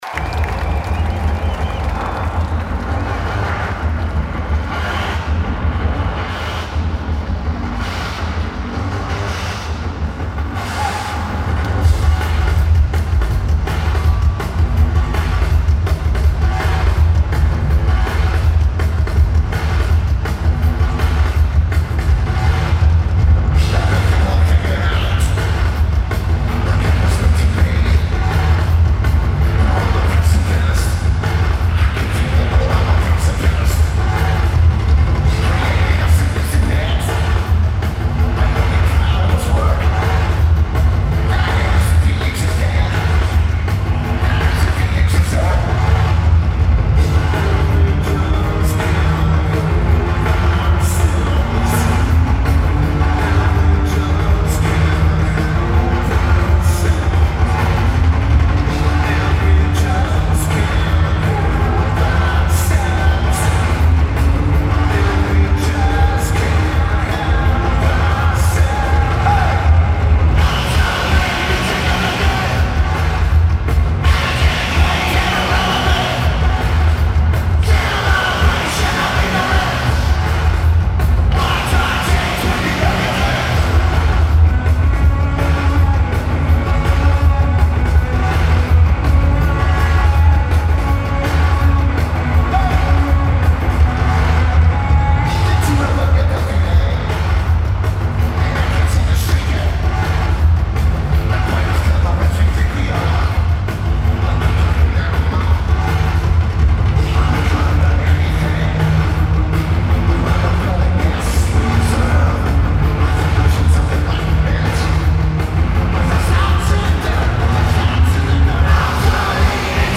Royal Albert Hall
Lineage: Audio - AUD (Shure MV88 Motiv Mic + iPhone)